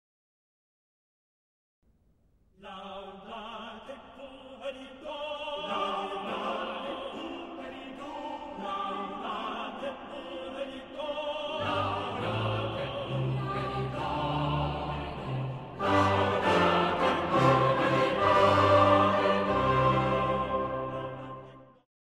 Je hoort een buitengewoon opzwepend ritme.
De eerste maat is eenstemmig, dan wordt het tweestemmig, weer tweestemmig, dan vierstemmig en de laatste keer is het stukje zelfs achtstemmig.
In de andere stemmen hoor je voortdurend tegenstemmen in een bijna gelijk ritme.